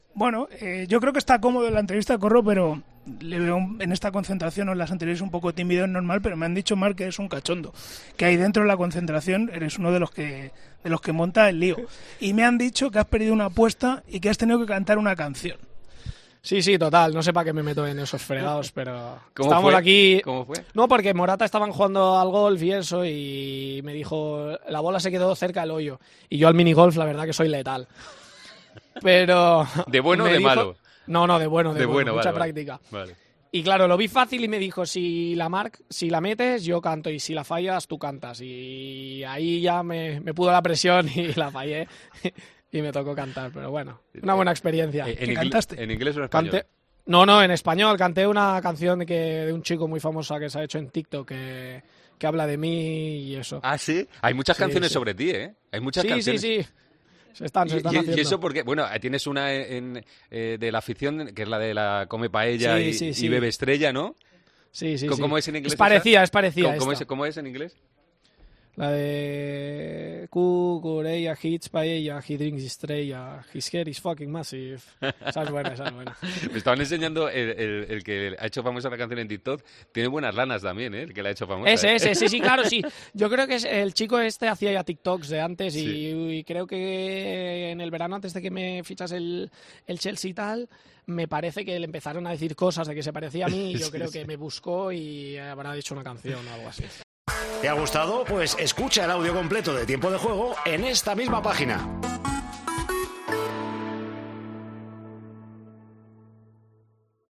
El lateral izquierdo de la selección española pasó este sábado por los micrófonos de El Partidazo de COPE para analizar su gran irrupción durante la Eurocopa.